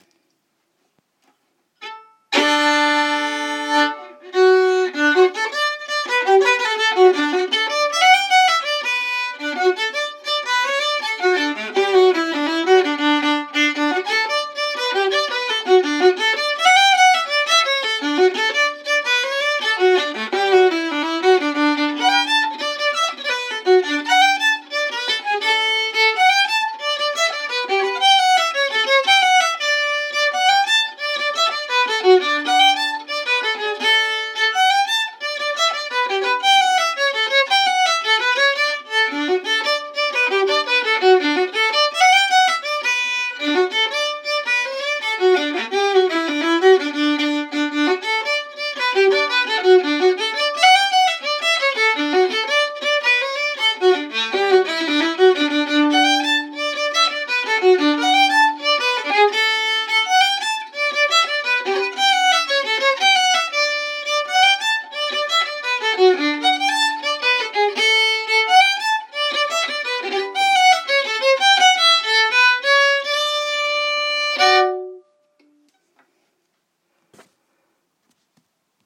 Tune